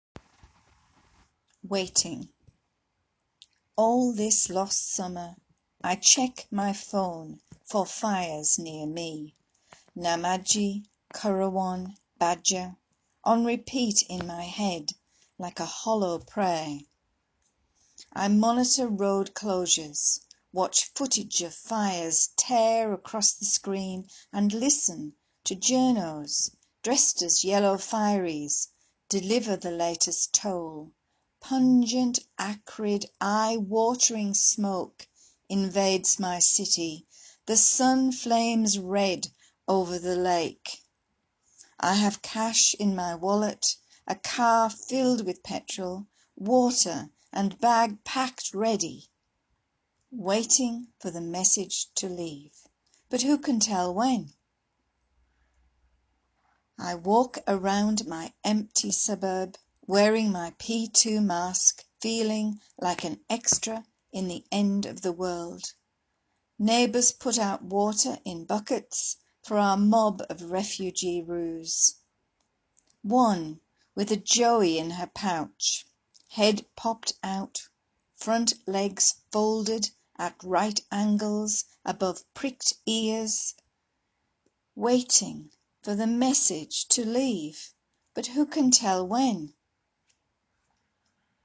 Plus our editors’ read their poems on Australia’s bushfires.
Fire poems by some of the NVQ editors